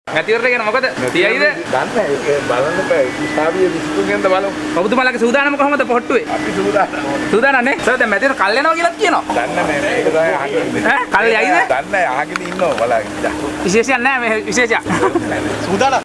ඔහු මෙම අදහස් පළ කළේ අද නෙ`ඵම් මාවත පක්ෂ මූලස්ථානයේ පැවති සාකච්ඡාවකට එක්වීමෙන් අනතුරුවයි.